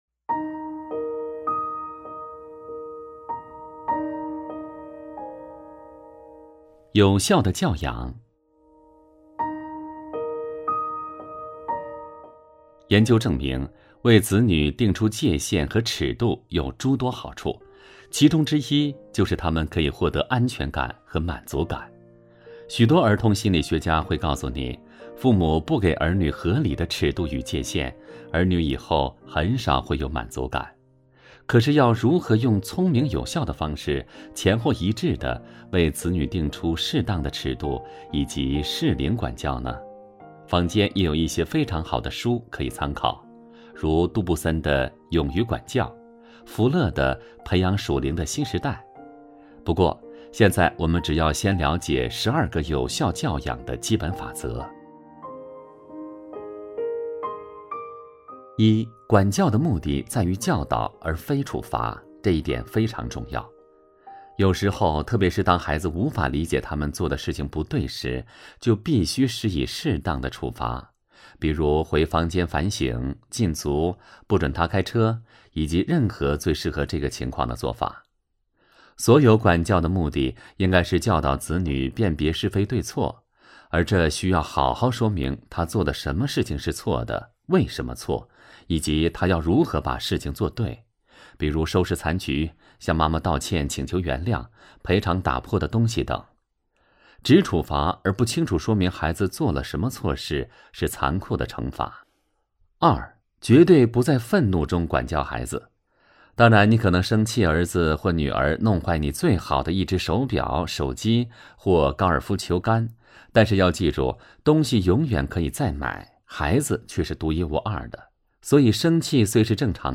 首页 > 有声书 > 婚姻家庭 | 成就好爸爸 | 有声书 > 成就好爸爸：15 十二个有效教养的基本法则（上）